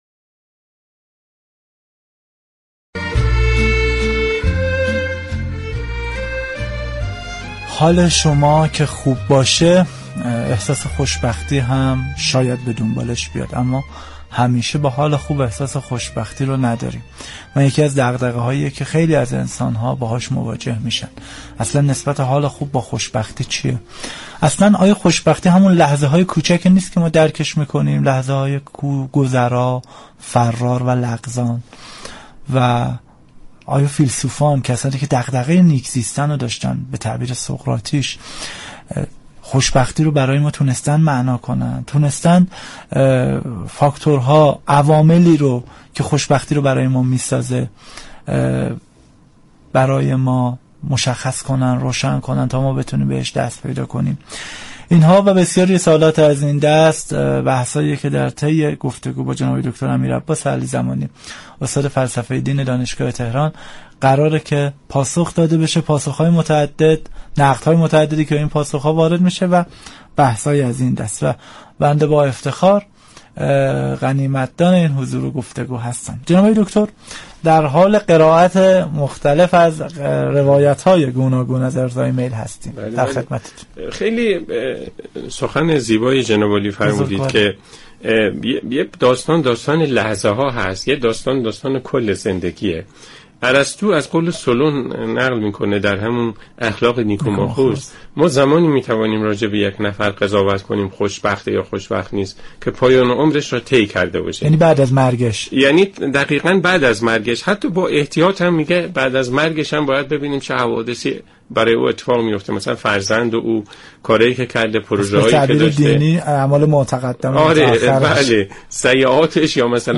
كیمیا برنامه ای از گروه جامعه و فرهنگ است كه هر یكشنبه از ساعت 21:00 تا 22:00 پخش می شود .
درباره خوشبختی و معنای آن گفتگو می كند .